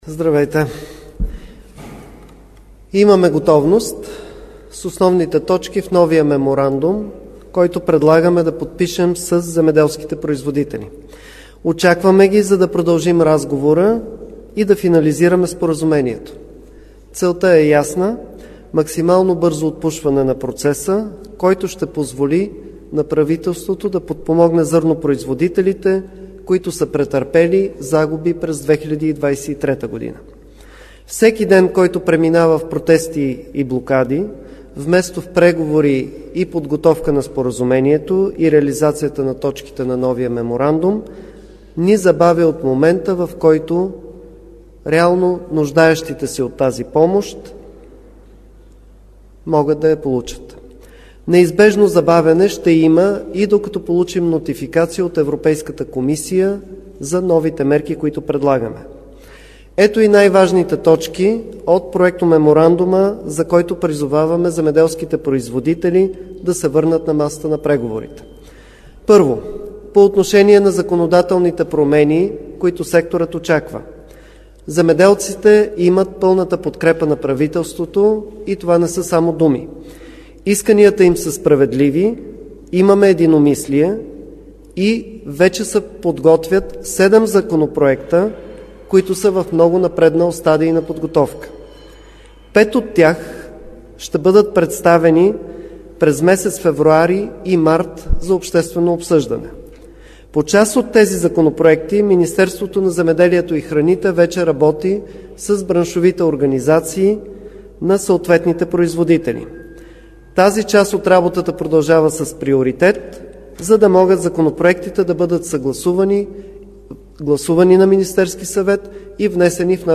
Директно от мястото на събитието
16.10 - Брифинг на министър-председателя Николай Денков за протеста на земеделците. - директно от мястото на събитието (Гранитна зала на МС)